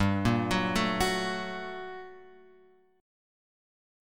GmM9 chord {3 5 4 3 3 5} chord